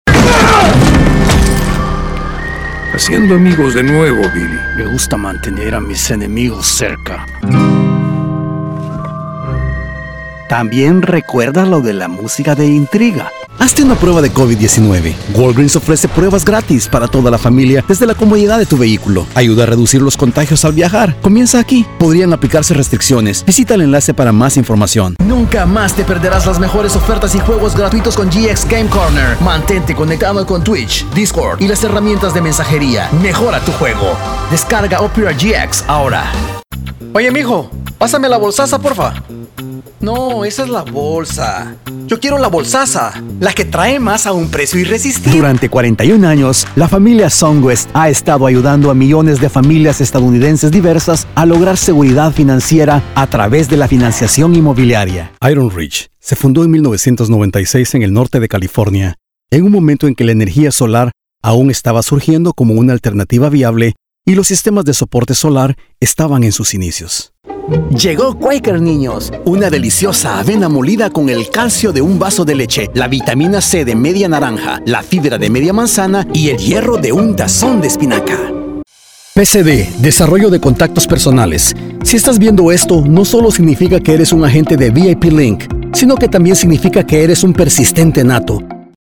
Bilingual clear voice, dynamic, positive, emotive, promotional, corporative, friendly.
Spanish - (Neutral - USA) Reel 2
My PRO STUDIO is equipped with SOURCE CONNECT STANDARD (available at an extra rate), PRO TOOLS, AKG 414 B/ULS mic, and AVID MBOX STUDIO.